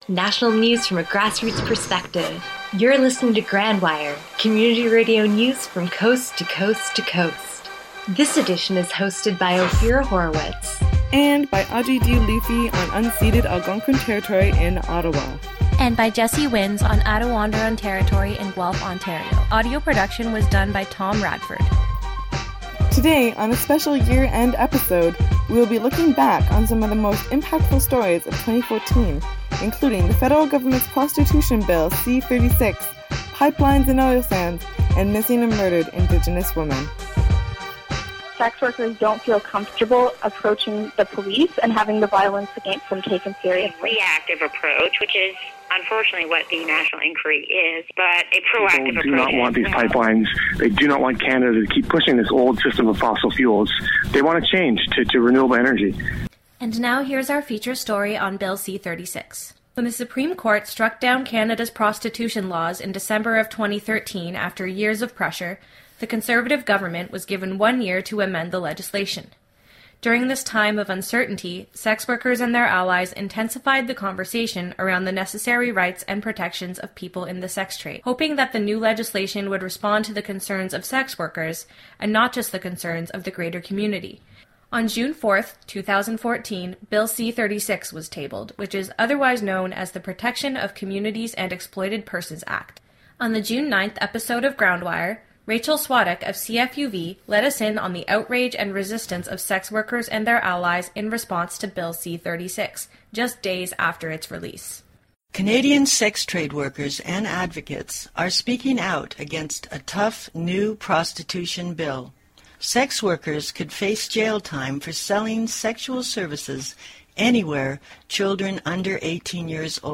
GroundWire: National Radio News